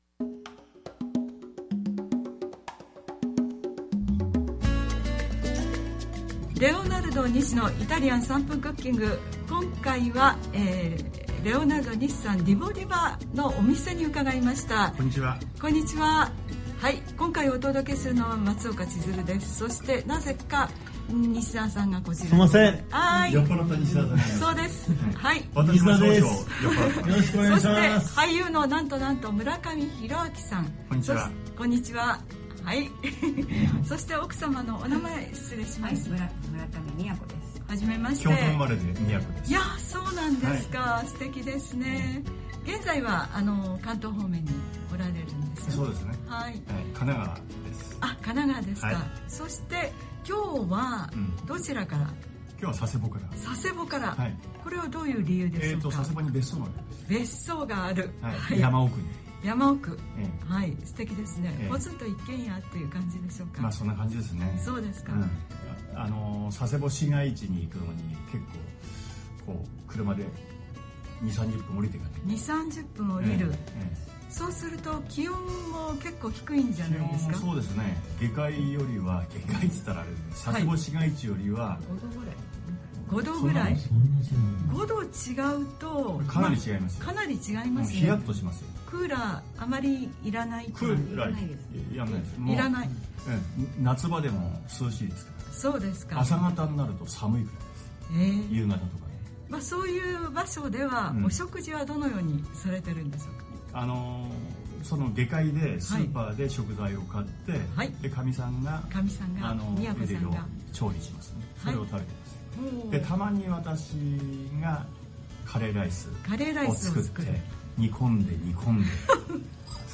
2021-9-3OA 俳優の村上弘明さんがゲストです！
ゲスト）　　　　村上弘明さん